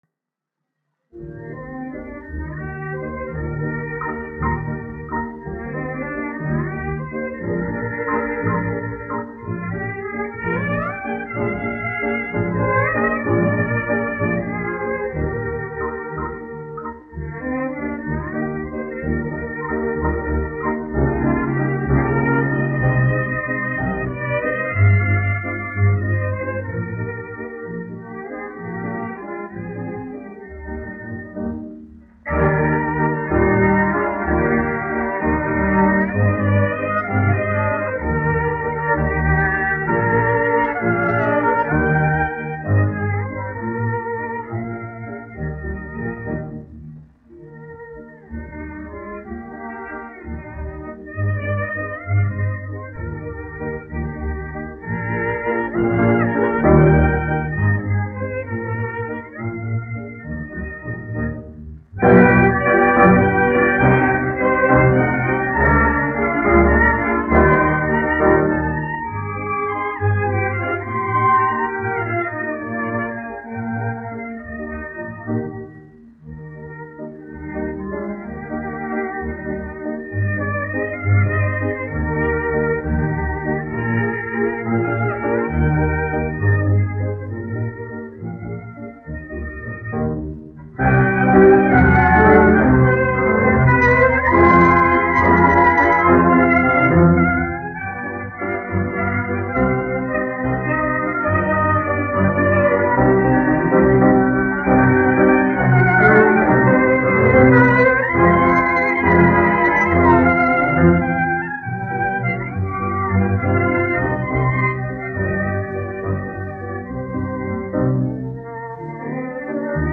1 skpl. : analogs, 78 apgr/min, mono ; 25 cm
Populārā instrumentālā mūzika
Valši
Skaņuplate